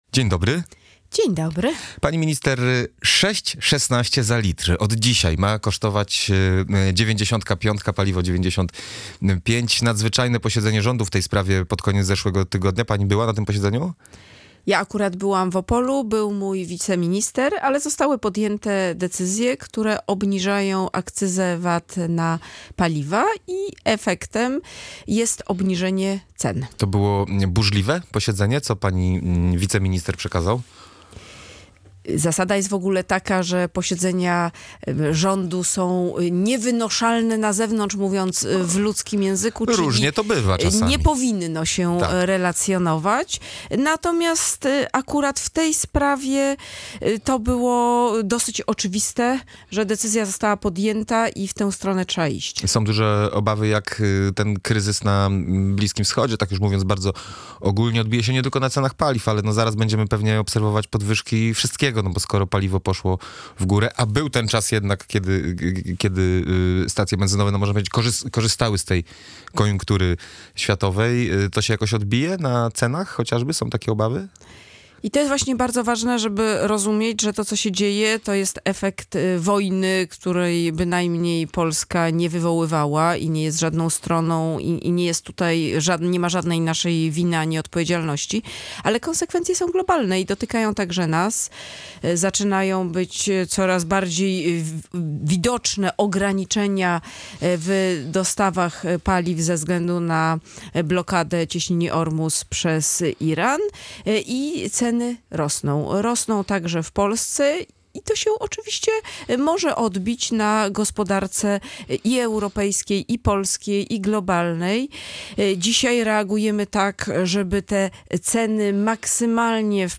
Opowiadam się za tym, aby wprowadzić podatek od nadmiarowych zysków firm – mówiła w audycji „Gość Radia Gdańsk” Katarzyna Pełczyńska-Nałęcz, minister funduszy i polityki regionalnej, szefowa Polski 2050.